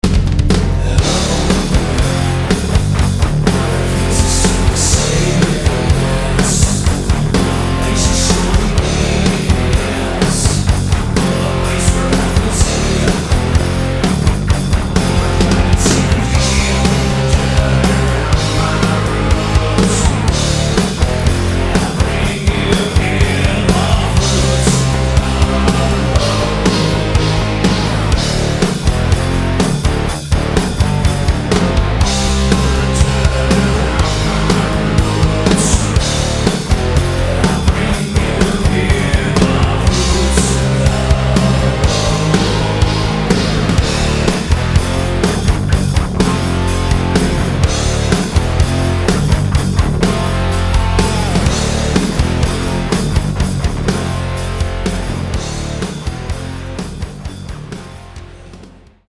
The music is best described as Gothic/wave.